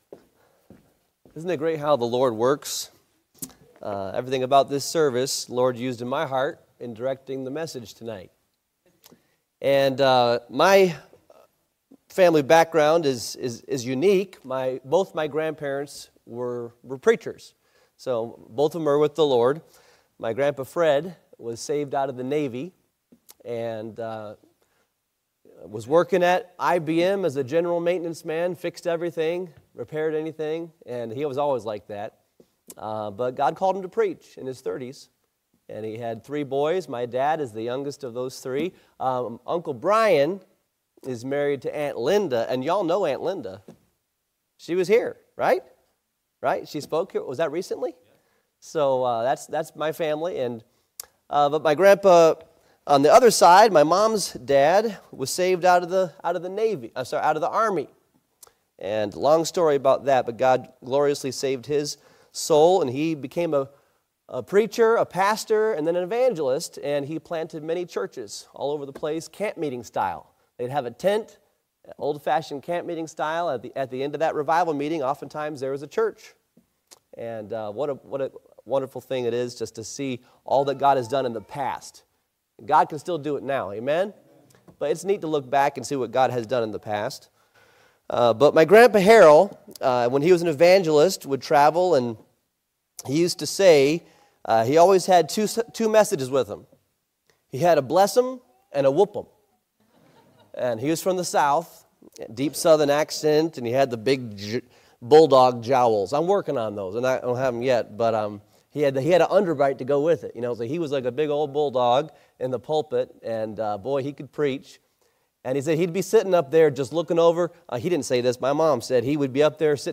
Series: 2025 Spring Revival